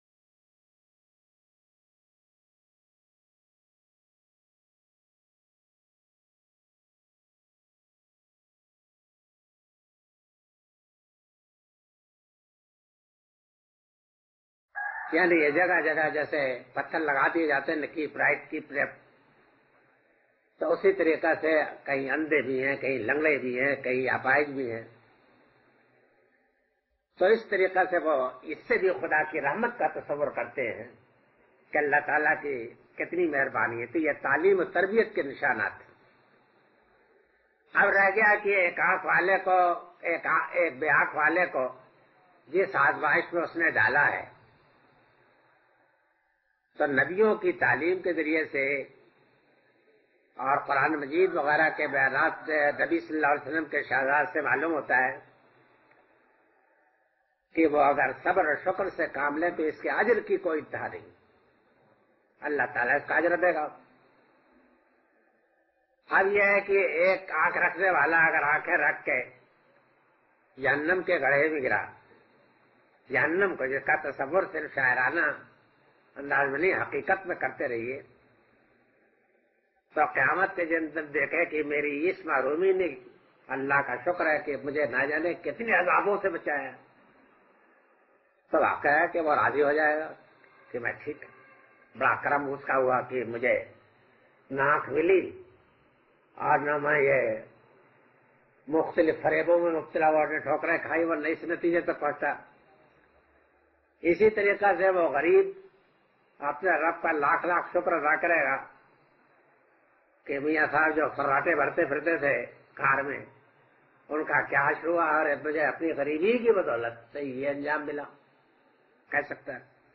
Moulana Amin Ahsan Islahi's Dars-e-Qur'an.